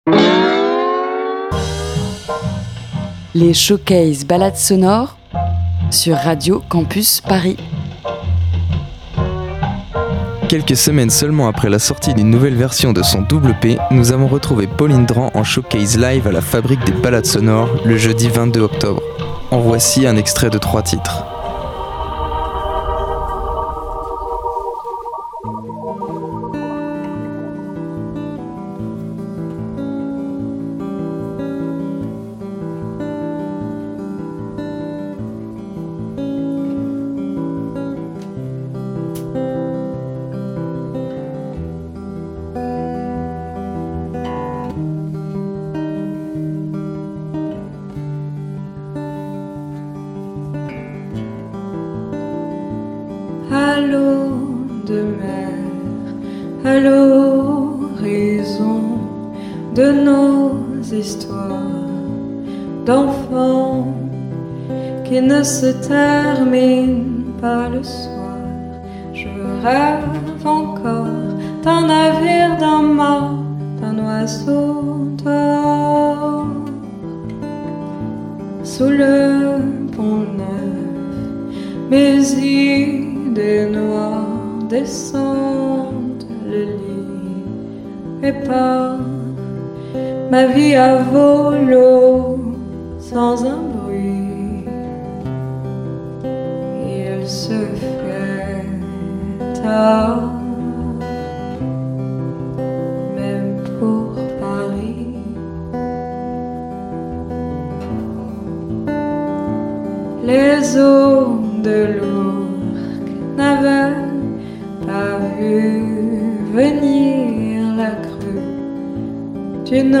folk urbain sensuel et sensible